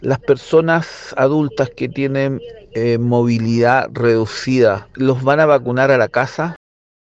Auditores de Radio Bío Bío hicieron llegar sus consultas.